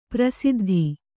♪ prasiddhi